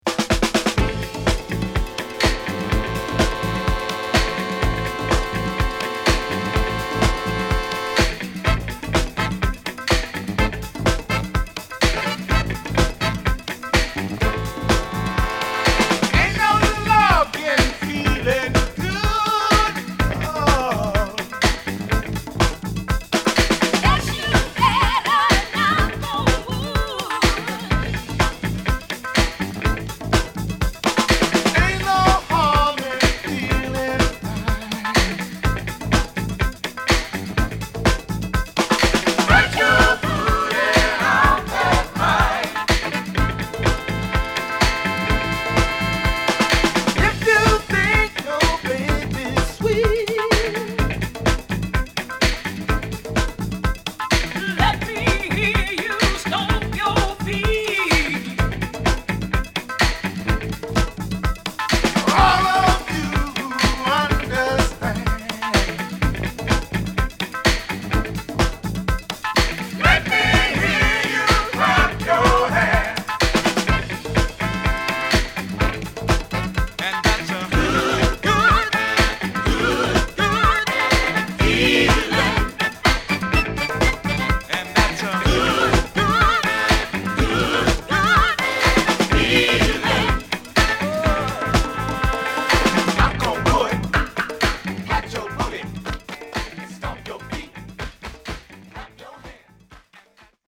西海岸発のミクスチャーバンド